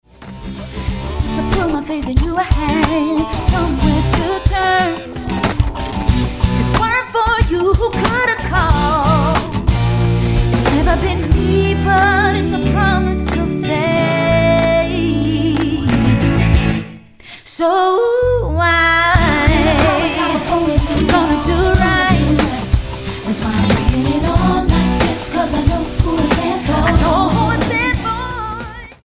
STYLE: R&B